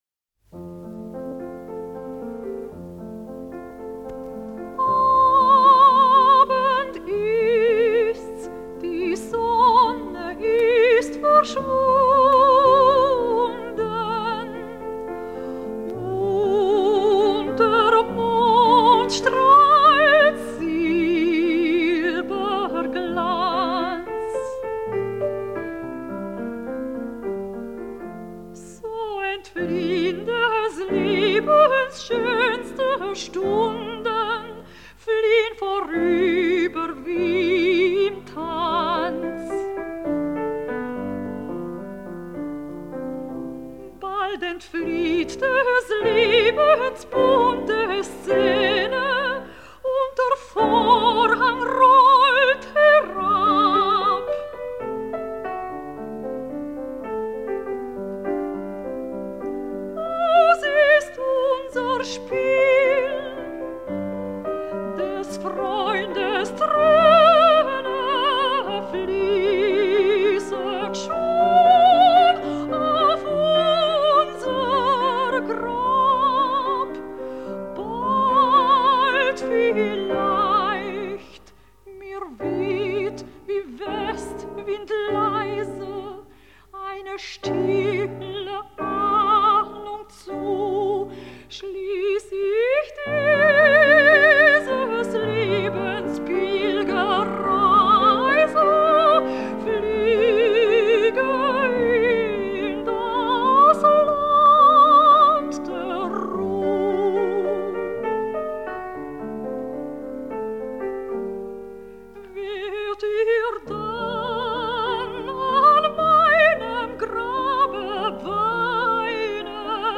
Maria Stader, Soprano. W.A. Mozart: Abendempfindung, an Laura. Lied in F major, K 523.
piano.